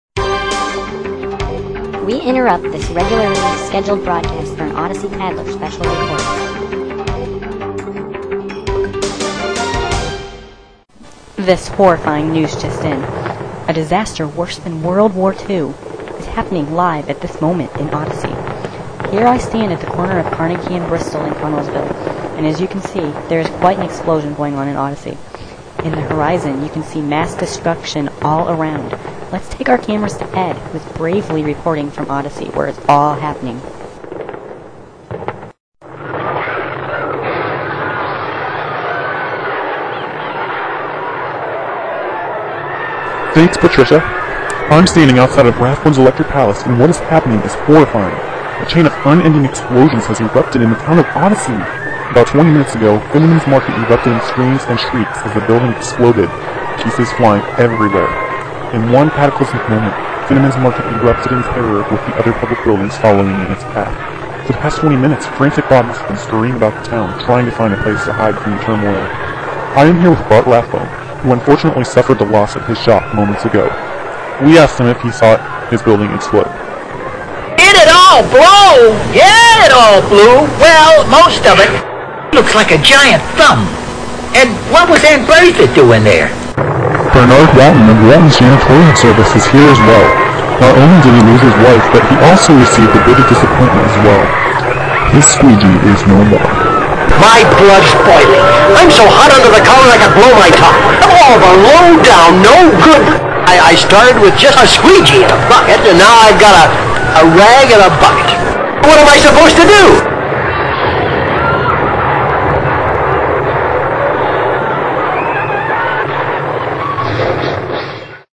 A wave of terror has hit the town of Odyssey! Residents are running, screaming, shoving each other out of the way to save their very lives. Odyssey is in chaos, and this live Odyssey Tattler report covers it all.
Writing the script for the two-minute broadcast took some effort, and mixing in appropriate sound effects wasn't easy.
Character's lines were cut out of AIO episodes to fit into the scene, and an odd comment on a thumb is in there!